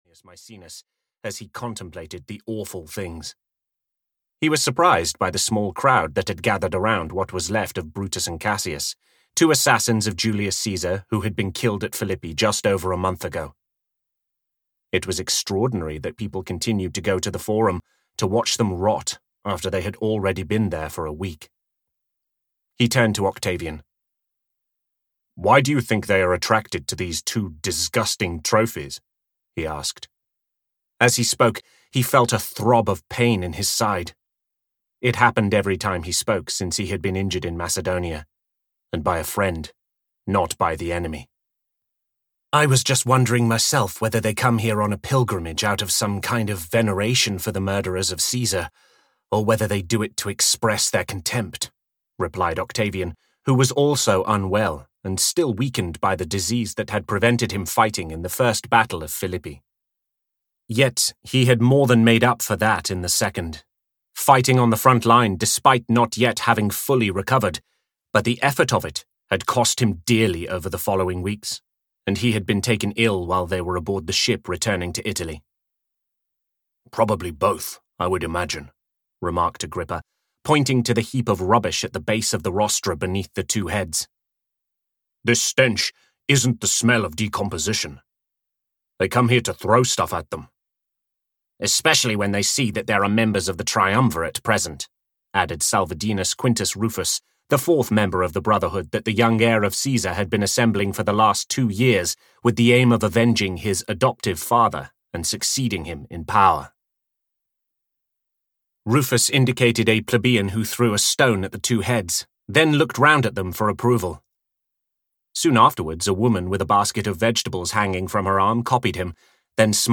Emperor (EN) audiokniha
Ukázka z knihy